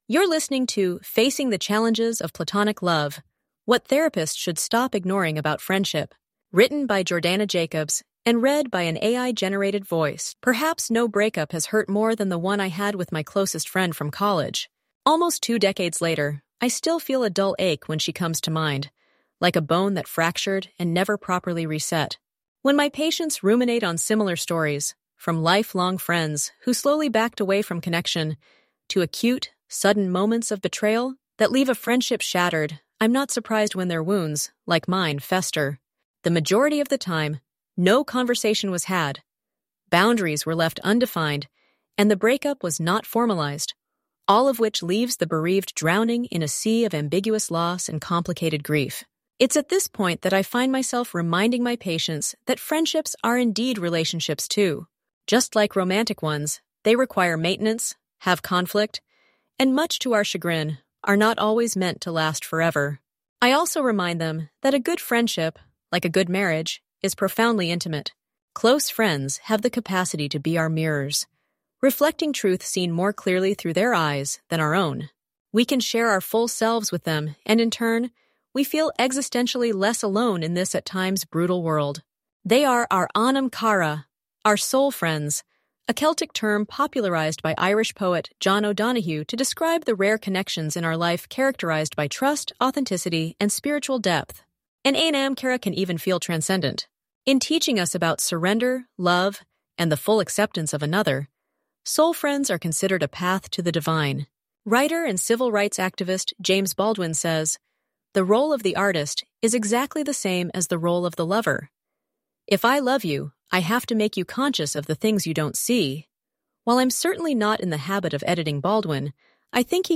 Enjoy the audio preview version of this article—perfect for listening on the go.